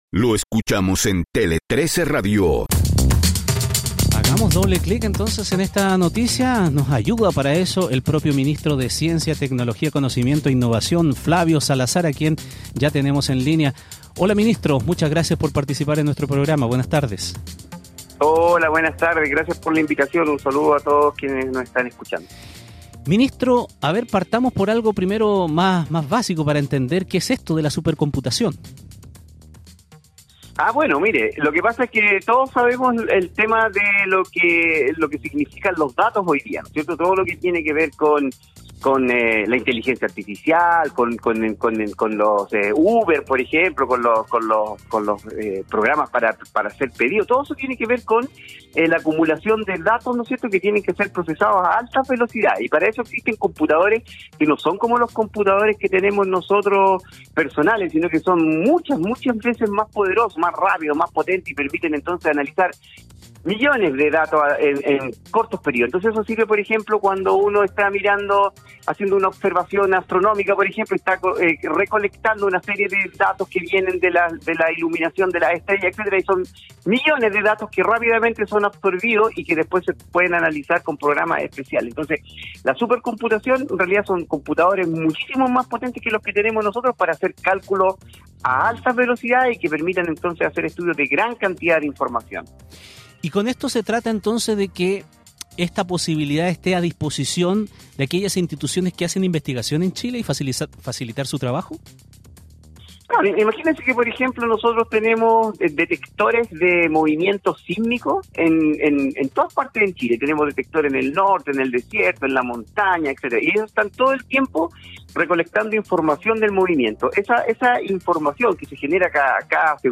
En Doble Click de Tele13 Radio, Ramón Ulloa entrevista al ministro de Ciencia Flavio Salazar respecto a la firma del Convenio Marco del Laboratorio Nacional de Supercomputación, iniciativa interinstitucional que busca dar continuidad a largo plazo al Laboratorio Nacional de Computación de Alto Rendimiento (NLHPC), institución albergada en el Centro de Modelamiento Matemático de la Universidad de Chile.
El programa original lo pueden escuchar desde aquí